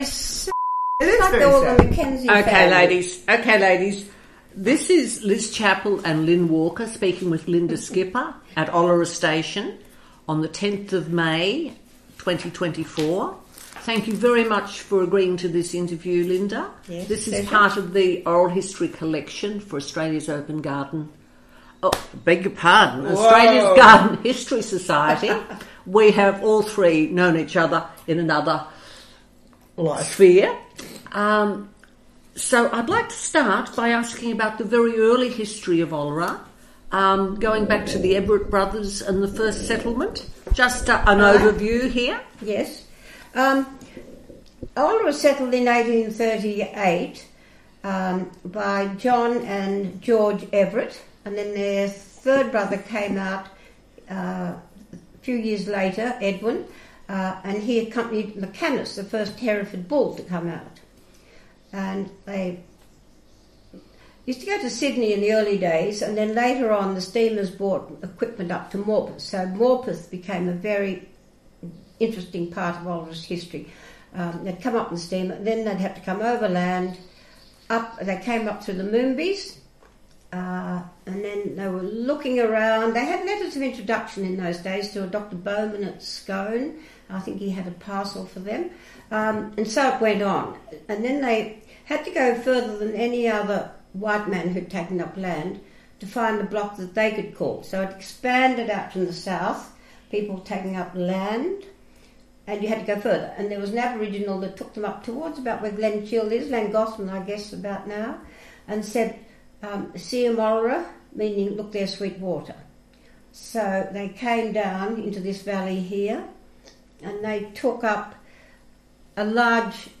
Interview Recording